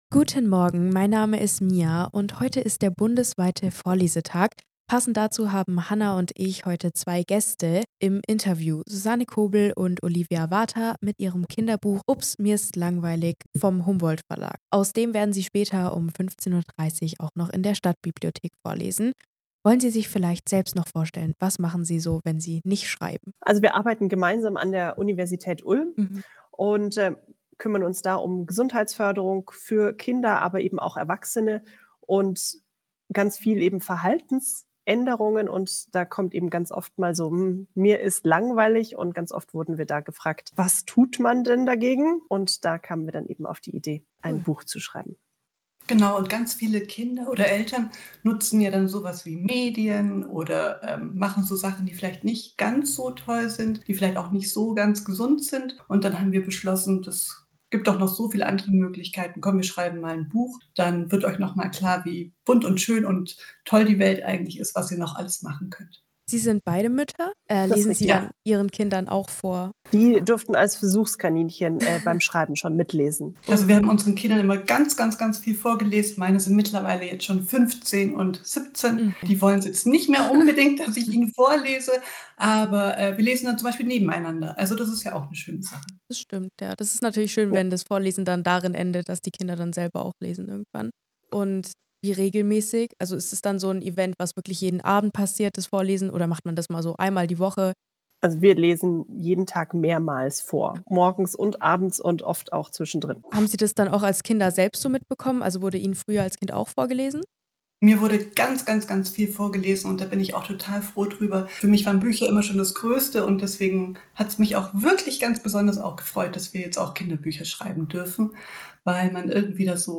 Interview Autoren.mp3